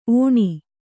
♪ ōṇi